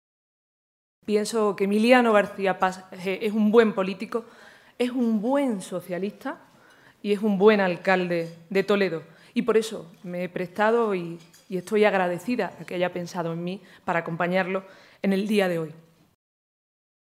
García-Page se pronunciaba de esta manera durante el ciclo de conferencias del Foro Nueva Economía, en Madrid, y en el que ha sido presentado por la Presidenta de Andalucía, Susana Díaz, que ha dicho del líder socialista castellano-manchego que es “un buen político, un buen socialista y un buen alcalde”.